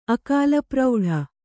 ♪ akālaprauḍha